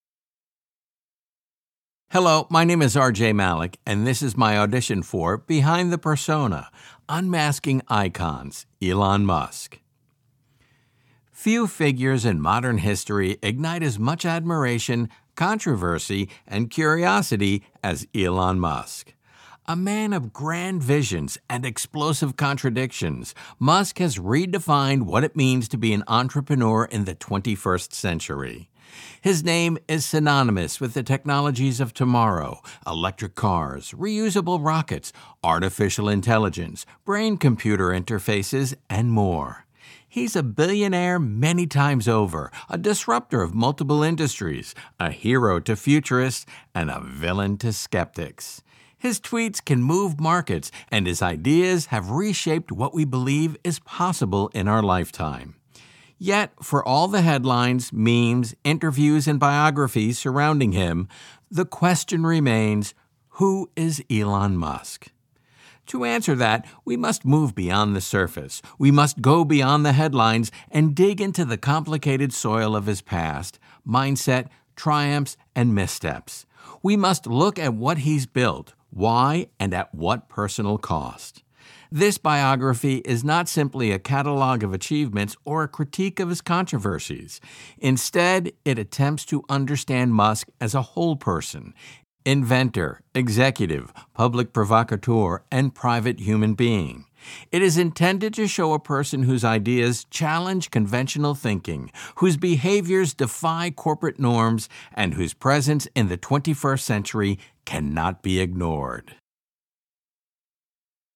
Male
Adult (30-50), Older Sound (50+)
I have a versatile style to my voiceover work. It can be powerful and commanding to casual and nonchalant. I can be the traditional professional announcer type to the guy next door.
Words that describe my voice are Natural, Strong, Athletic.
All our voice actors have professional broadcast quality recording studios.